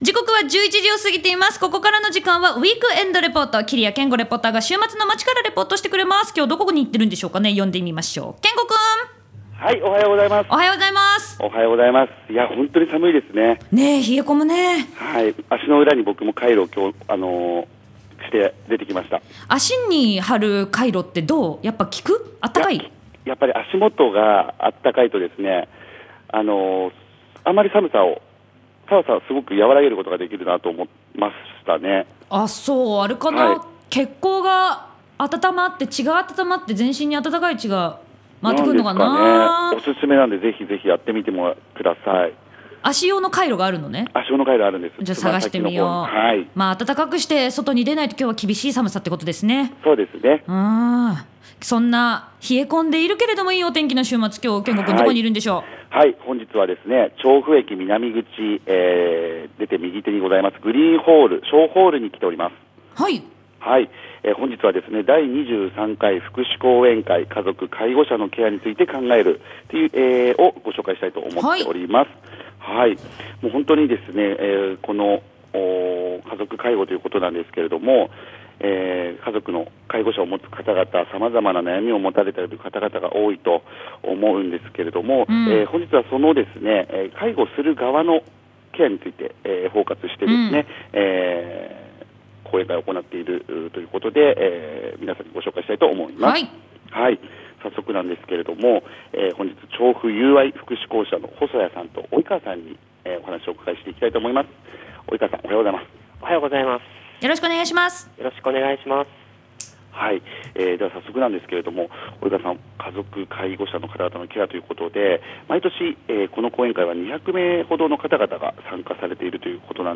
ラジオボンバー「ウィークエンドレポート」(レポーター